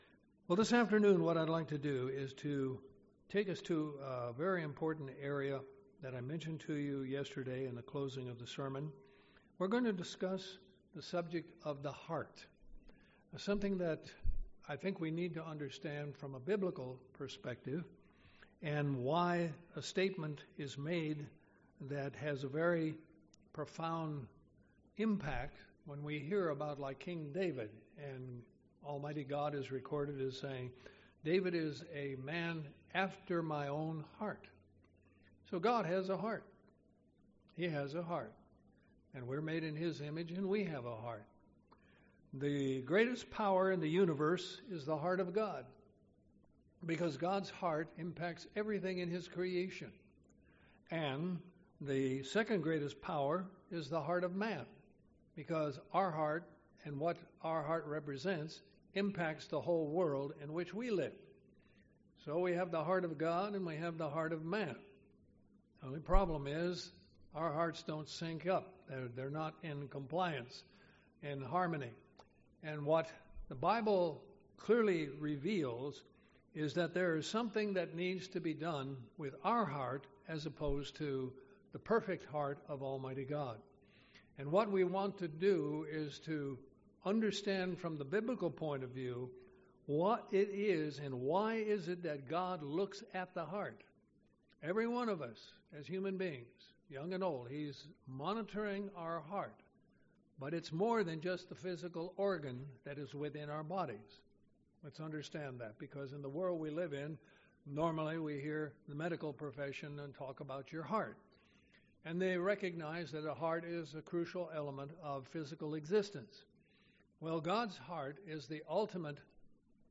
Sermons
Given in Columbus, GA